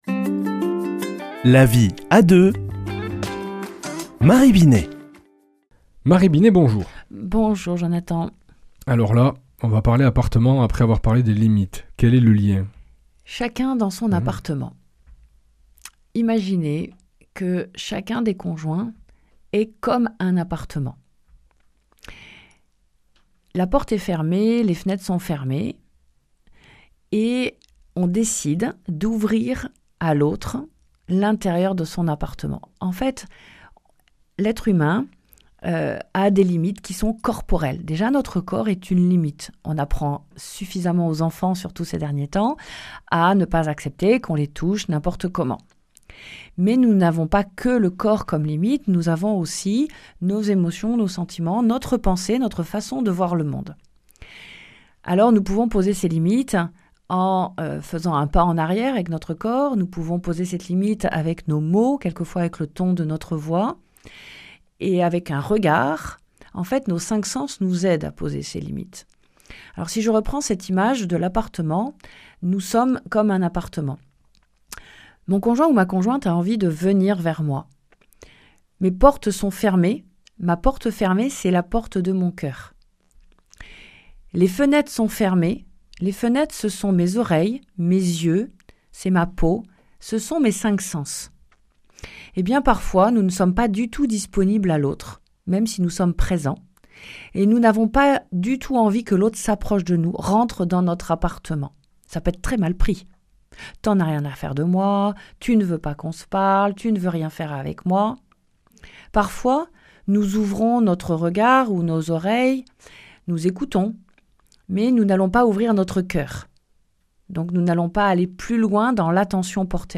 mardi 13 janvier 2026 Chronique La vie à deux Durée 4 min